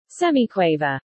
Semiquaver
semiquaver.mp3